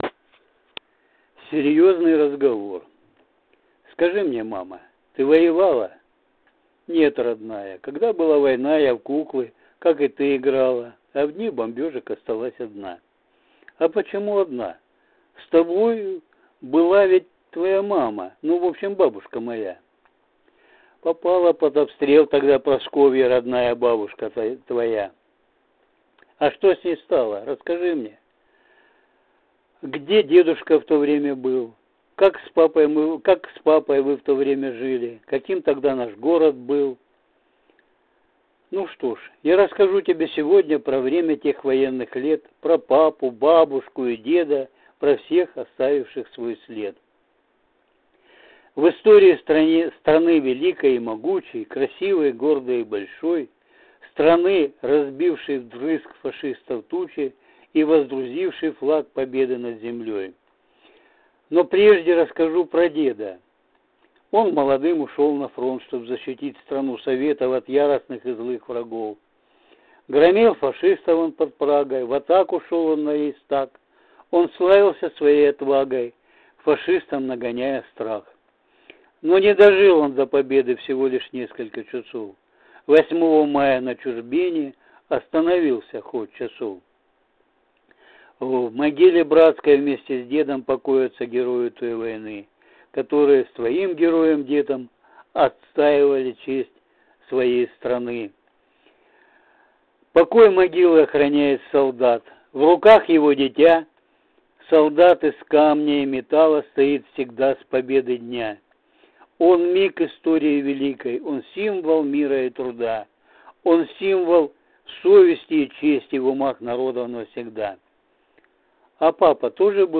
Читает автор